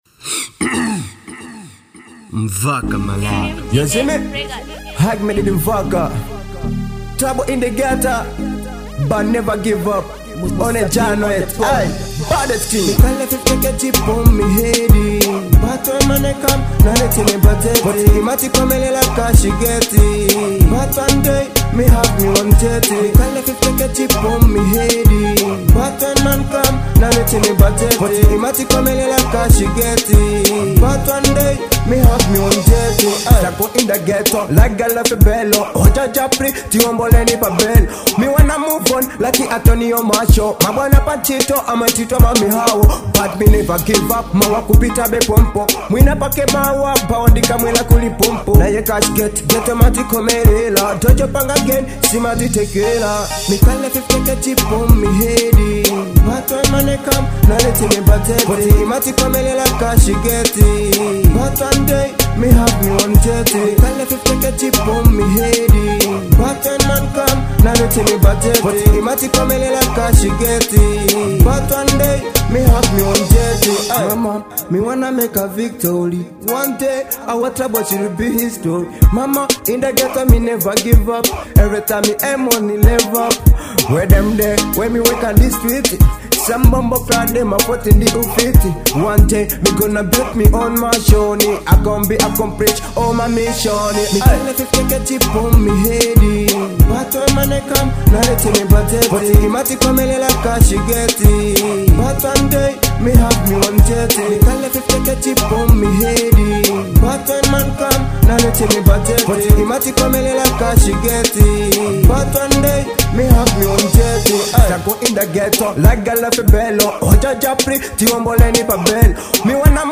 type: Dancehall